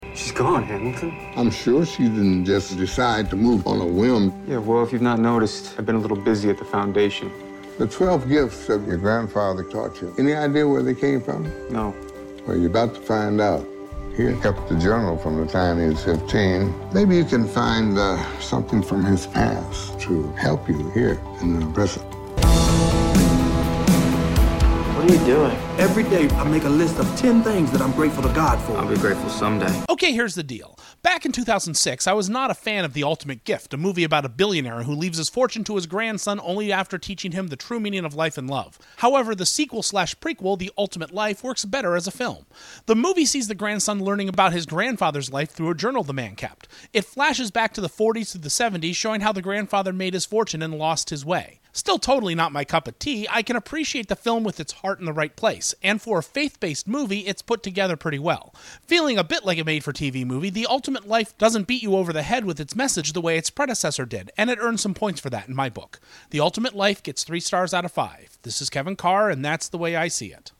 ‘The Ultimate Life’ Movie Review